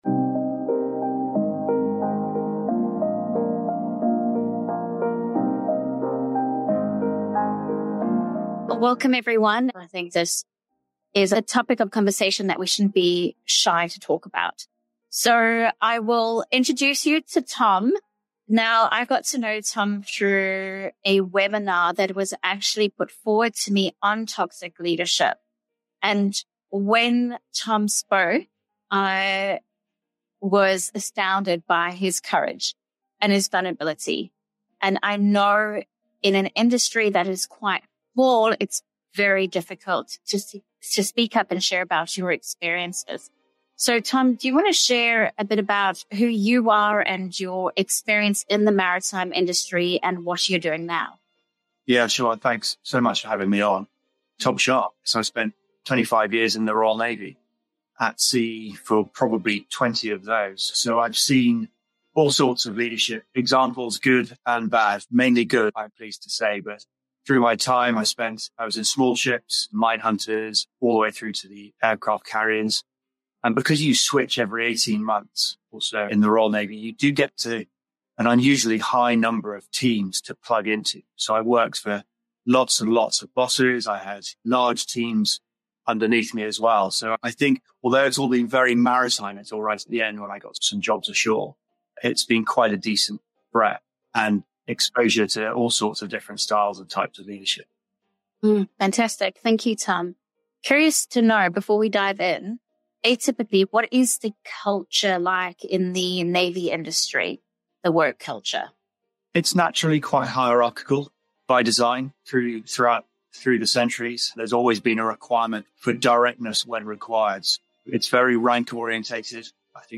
In this interview, examine the issue of power abuse within the Royal Navy due to hierarchical structures and toxic leadership. We discuss how to respond to and mitigate such behaviour, as well as methods of preventing and protecting team members.